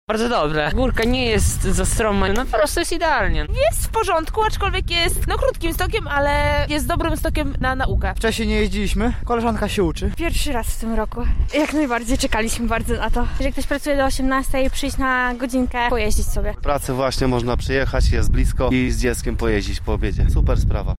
Zapytaliśmy korzystających ze stoku o wrażenia: